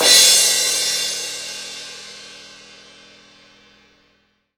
Index of /90_sSampleCDs/AKAI S6000 CD-ROM - Volume 3/Drum_Kit/DRY_KIT1